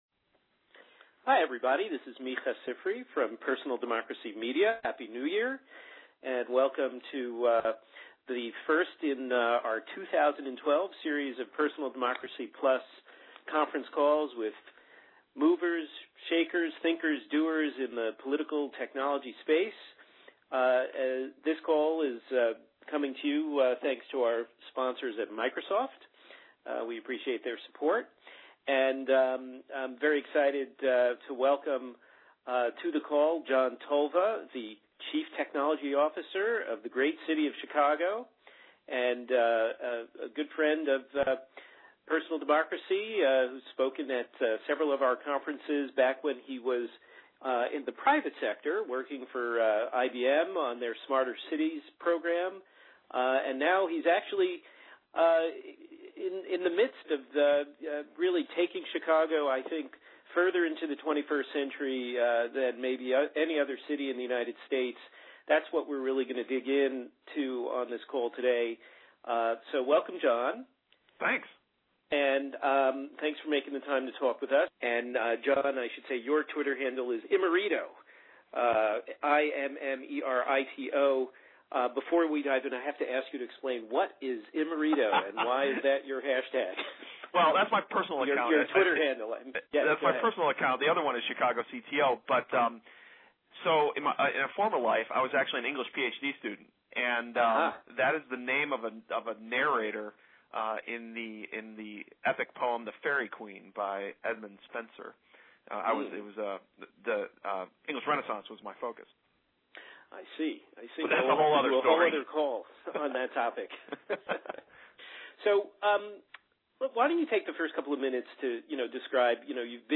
On this call, we dig in with John Tolva, CTO of Chicago and former head of IBM's Citizenship and Technology program, to learn how big data and lots of networking can enable a reinvention of urban life.